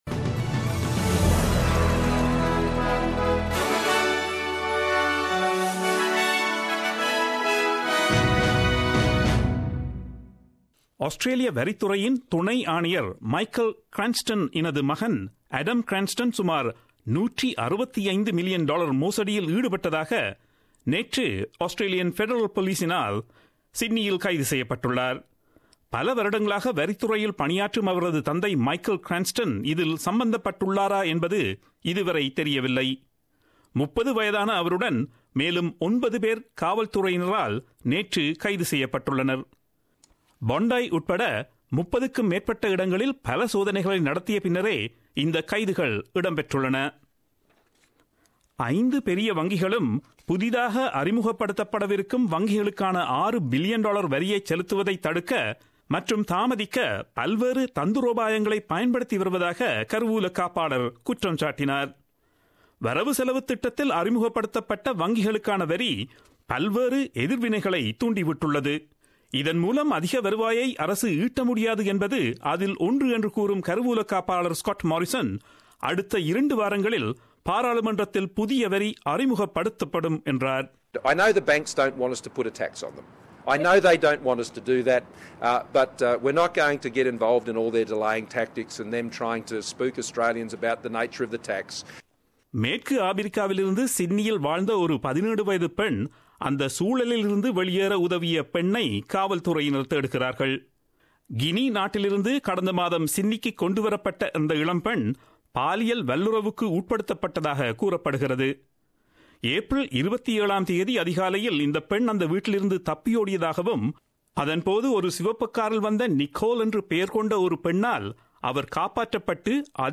Australian news bulletin aired on Friday 19 May 2017 at 8pm.